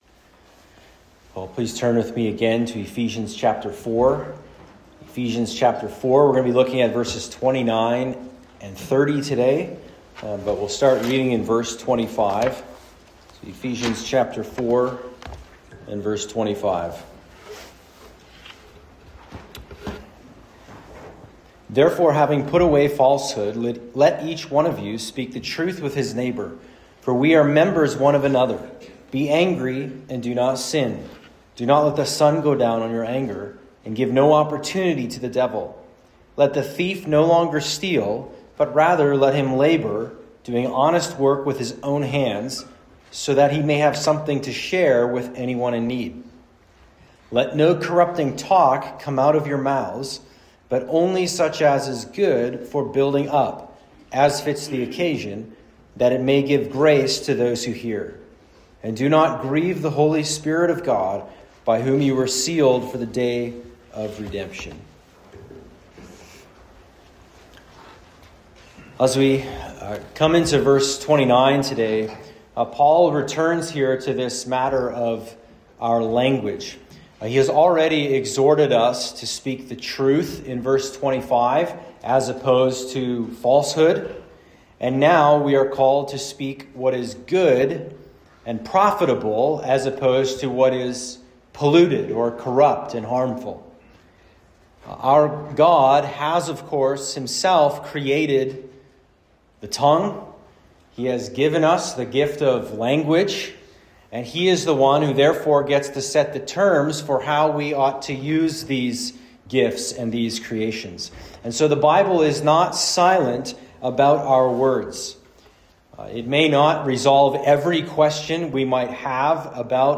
Sermons from the pulpit of Gospel of Grace Fellowship, a Reformed Baptist church in Weyburn, Saskatchewan.